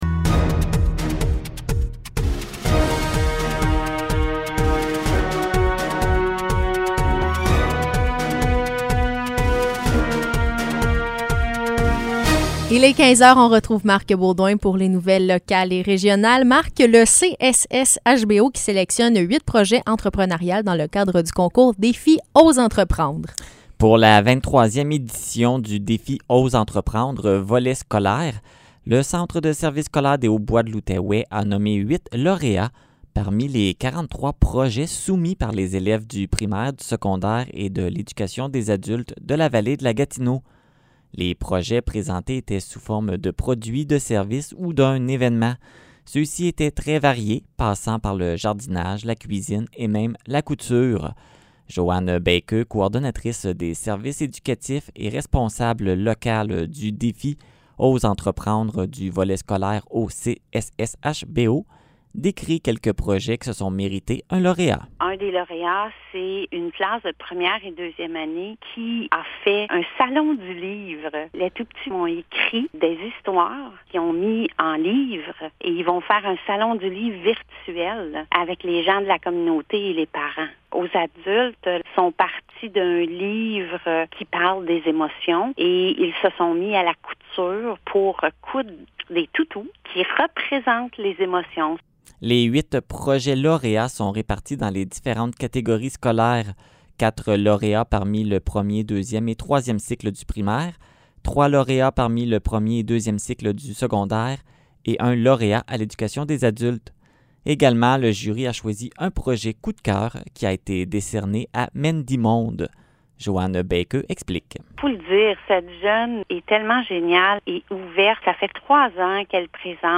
Nouvelles locales - 2 Avril 2021 - 15 h